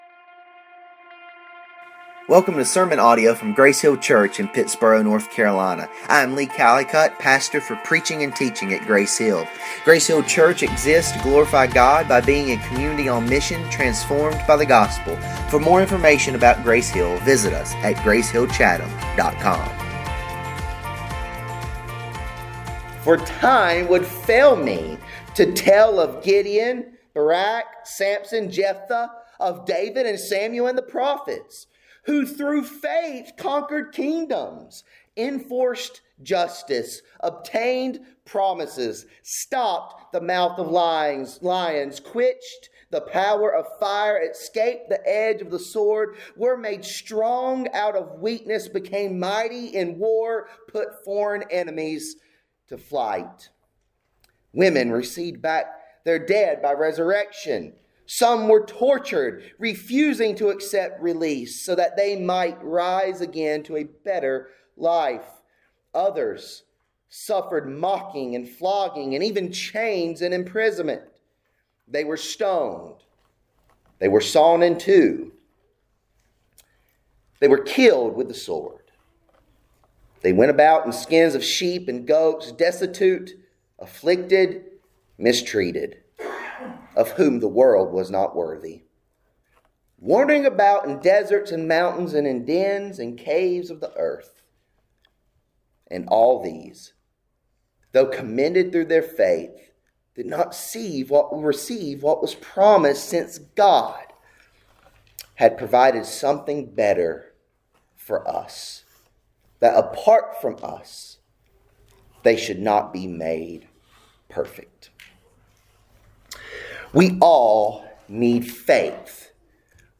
Live By Faith | Grace Hill Church Pittsboro, NC